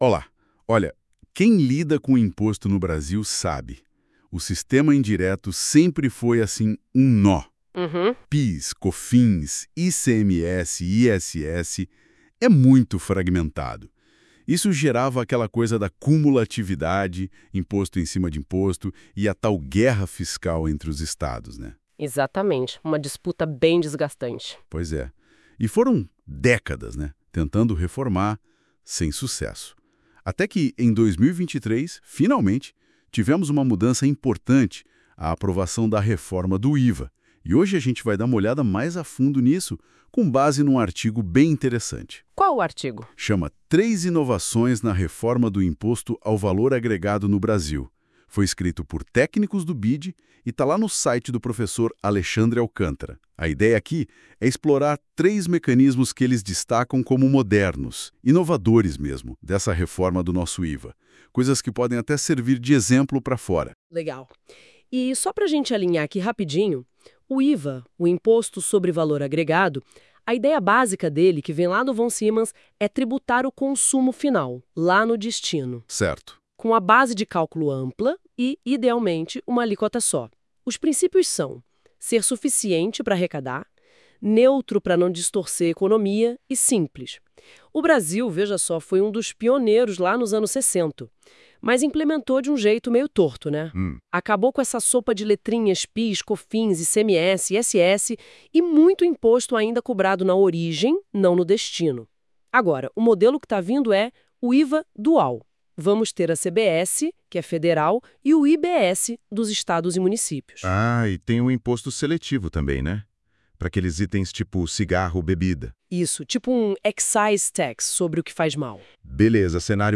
Confira o podcast preparado por IA com base no artigo